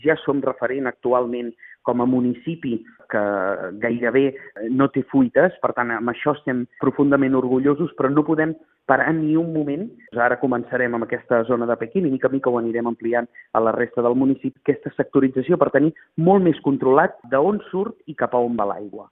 El tinent d’Alcaldia de Serveis públics indica que l’objectiu és continuar amb la segmentació de la xarxa a la zona centre, on hi ha el 63% dels abonats i s’hi registra el 48% de l’aigua subministrada.